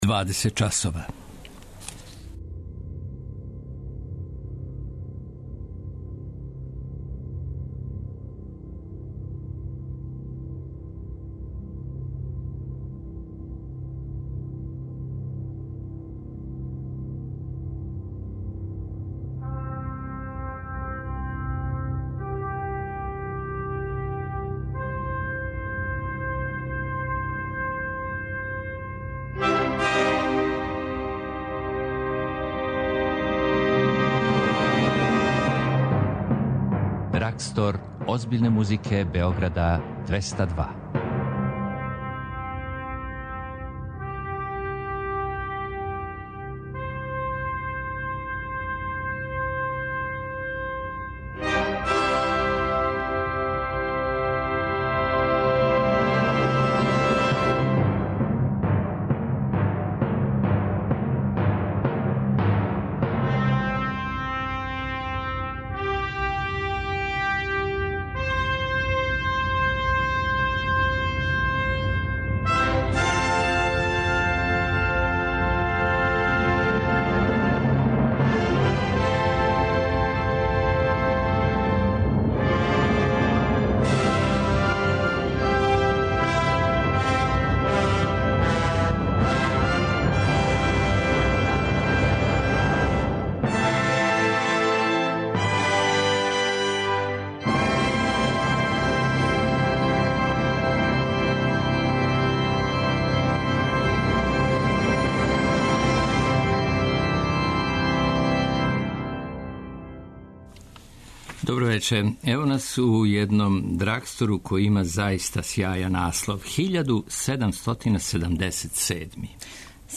виолина, кастањете, тестера
клавир
пратићемо концерт гудачког квартета